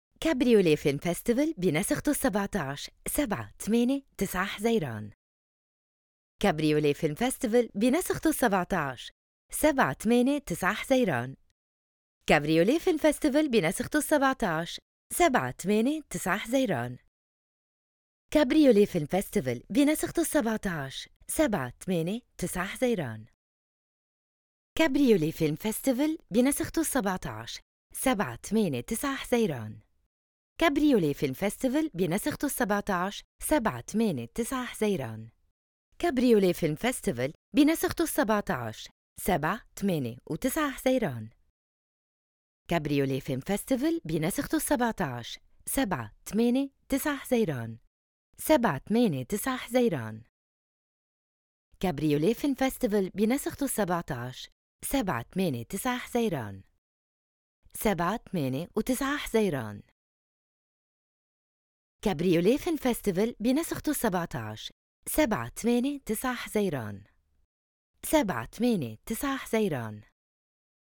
Female
20s, 30s
Bright, Bubbly, Confident, Corporate, Energetic, Friendly, Posh, Soft
Audiobook, Character, Commercial, Corporate, Documentary, Educational, IVR or Phone Messaging, Narration
Microphone: Apogee USB